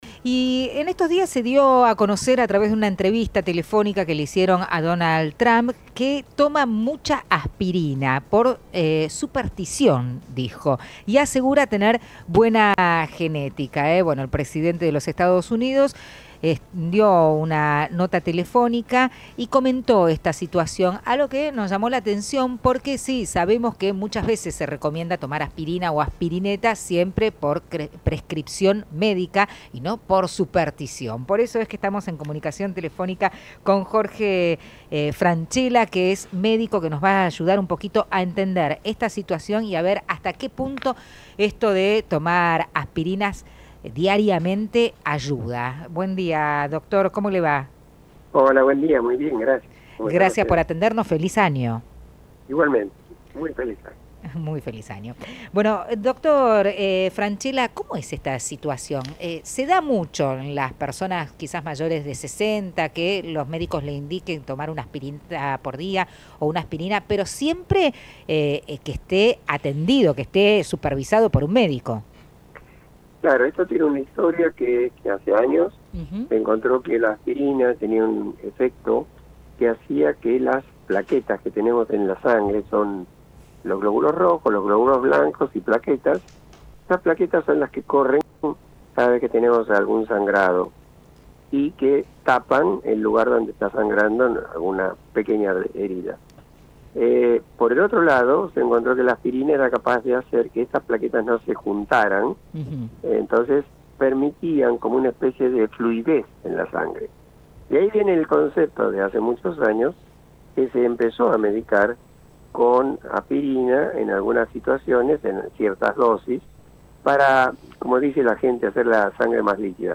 MAÑANA NACIONAL ENTREVISTA